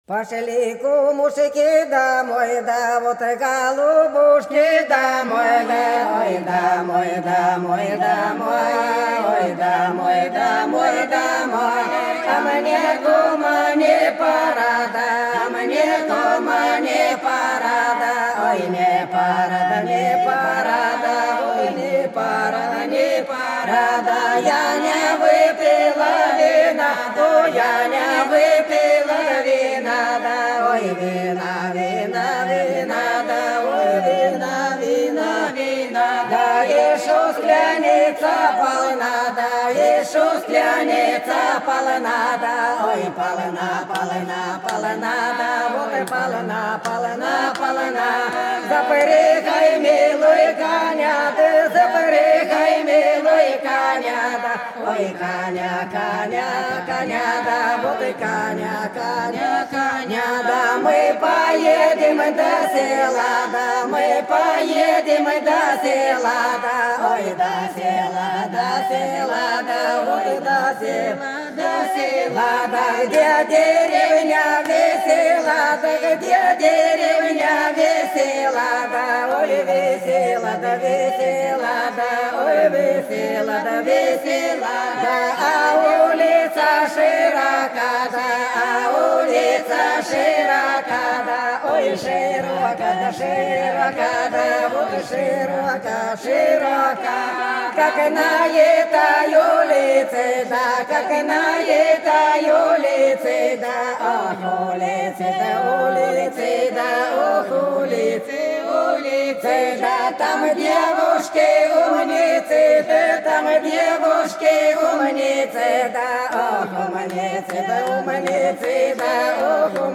Белгородские поля (Поют народные исполнители села Прудки Красногвардейского района Белгородской области) Пошли, кумушки, домой - плясовая